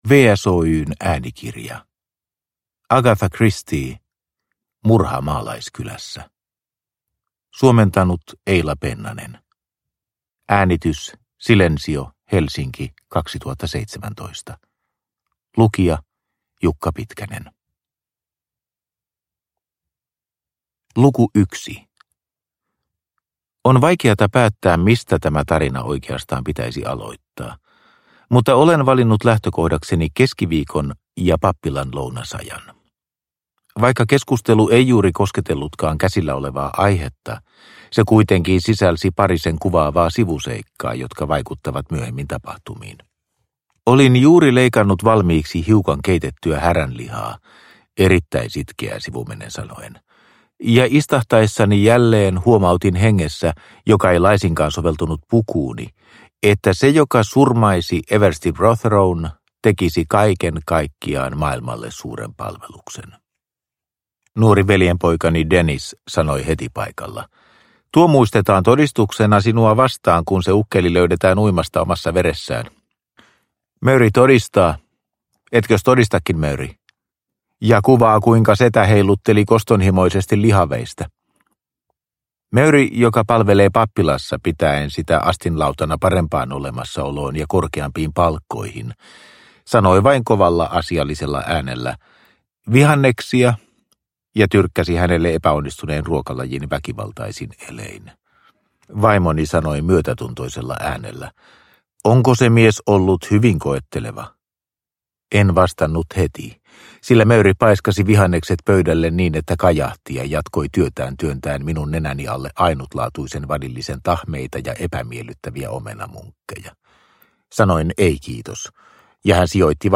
Murha maalaiskylässä – Ljudbok – Laddas ner